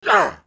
pain75_2.wav